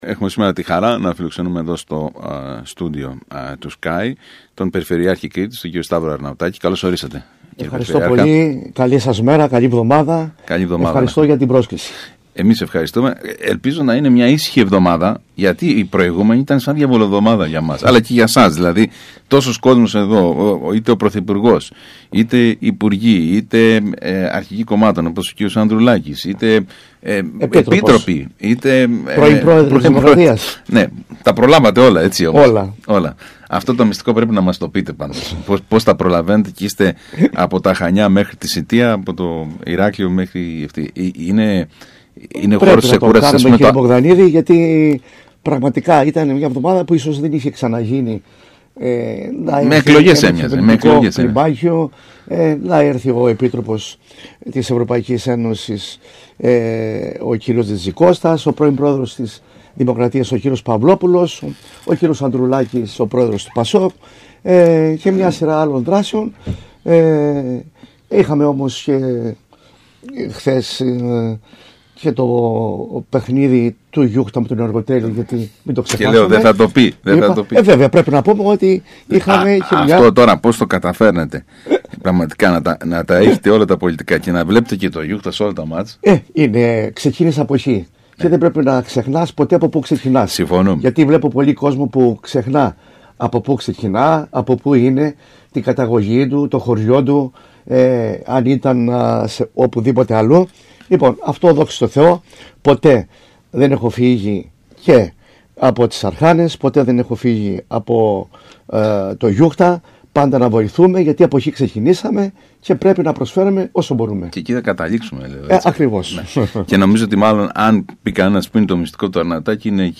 τόνισε μιλώντας στον ΣΚΑΪ Κρήτης 92,1